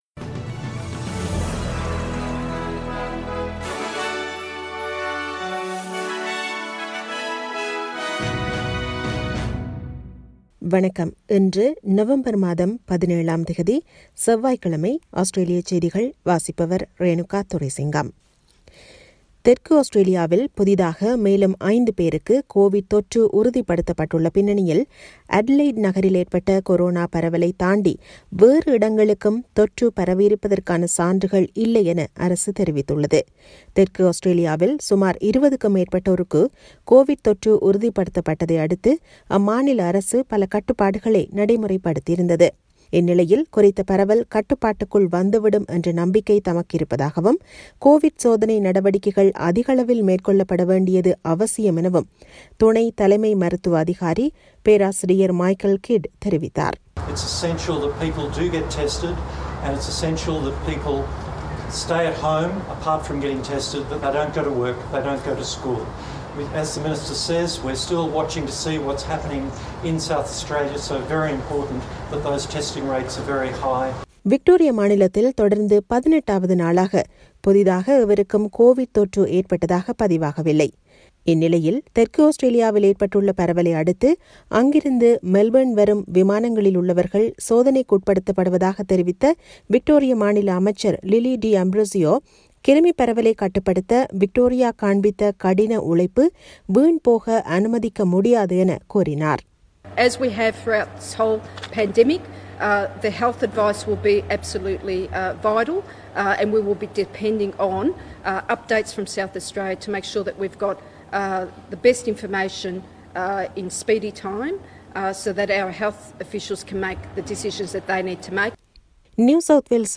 Australian news bulletin for Tuesday 17 November 2020.